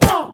Minecraft Version Minecraft Version 25w18a Latest Release | Latest Snapshot 25w18a / assets / minecraft / sounds / entity / witch / hurt1.ogg Compare With Compare With Latest Release | Latest Snapshot
hurt1.ogg